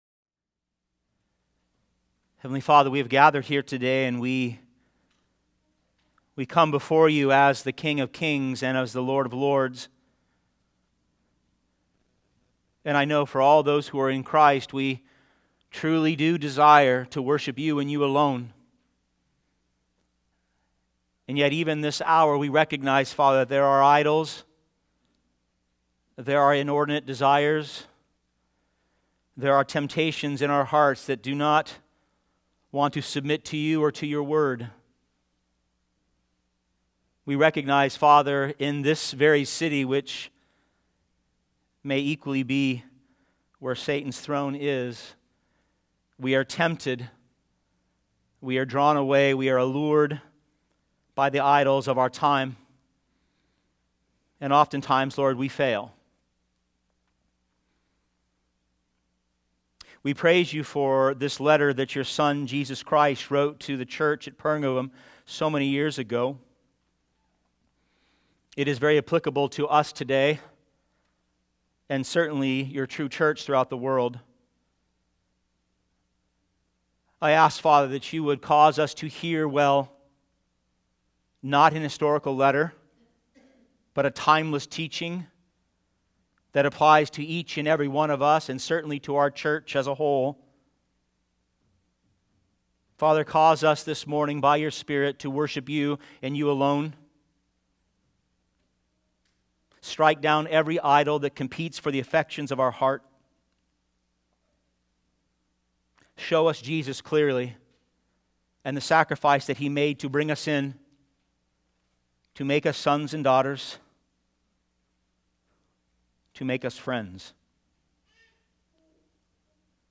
preaching on Revelation 2:12-17